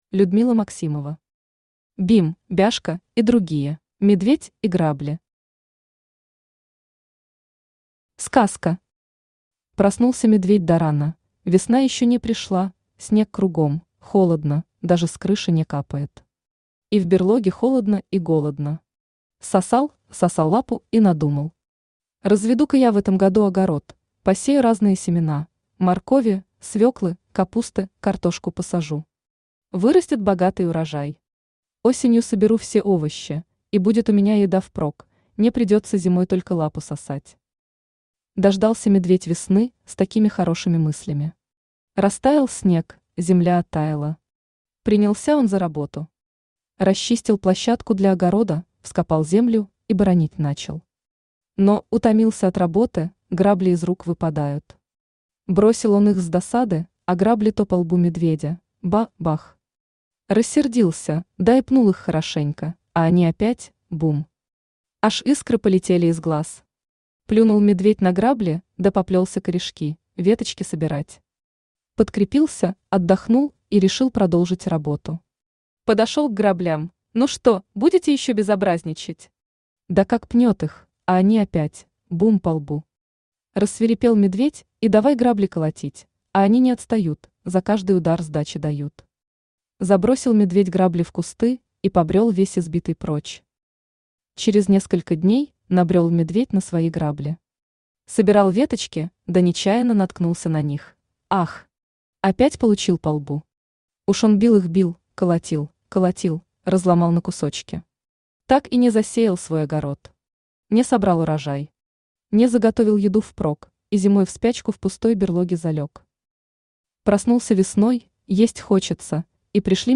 Аудиокнига Бим, Бяшка и другие | Библиотека аудиокниг
Aудиокнига Бим, Бяшка и другие Автор Людмила Максимова Читает аудиокнигу Авточтец ЛитРес.